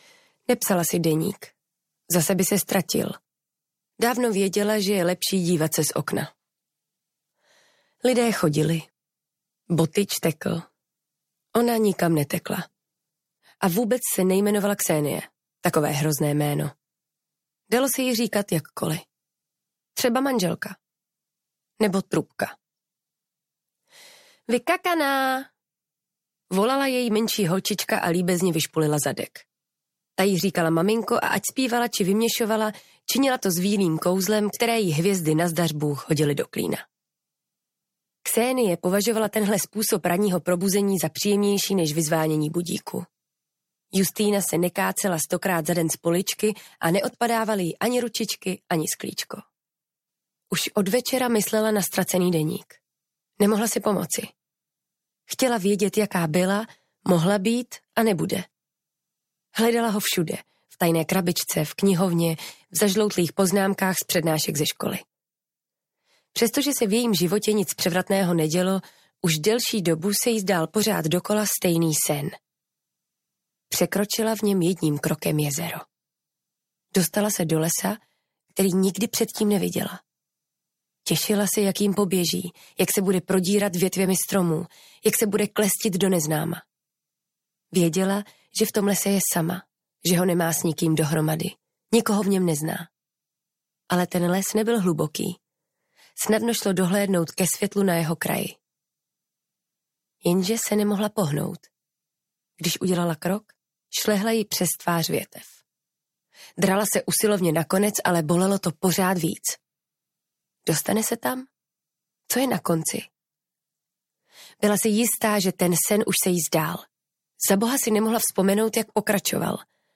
Deník šílené manželky audiokniha
Ukázka z knihy
• InterpretBerenika Kohoutová